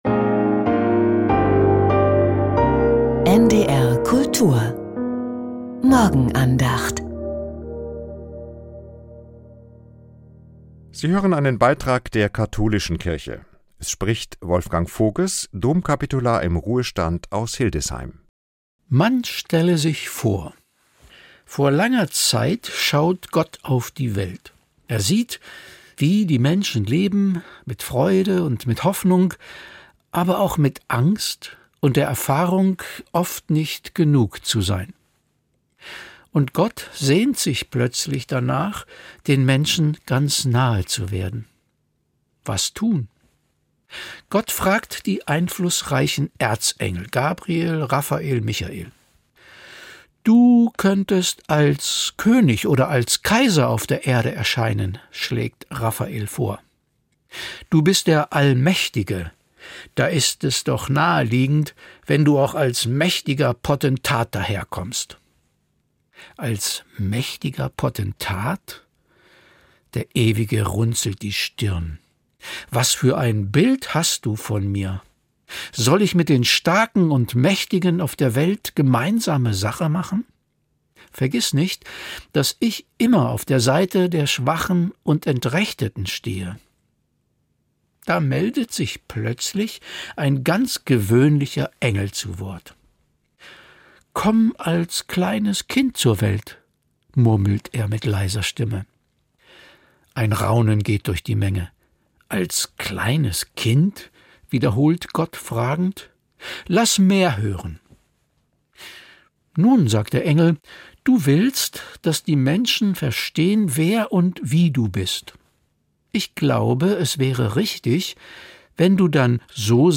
Die Morgenandacht